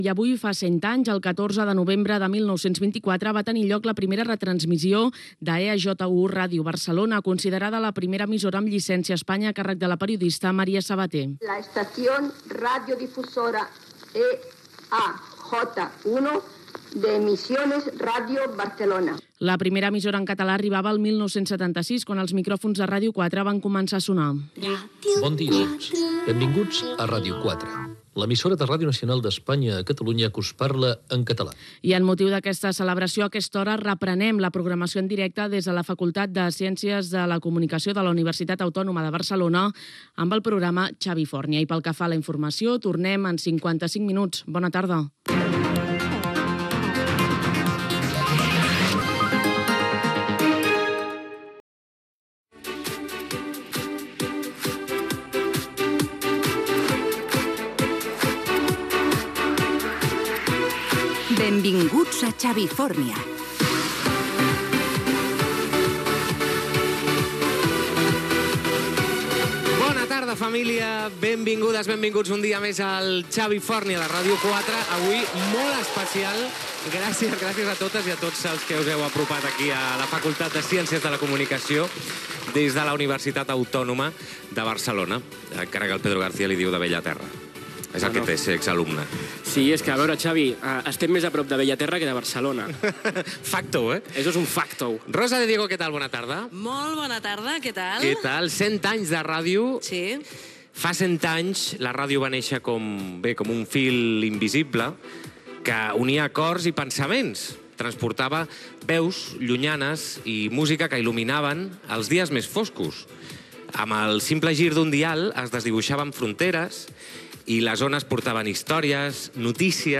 Entreteniment
Primer dia d'emissió de Ràdio 4 en DAB+.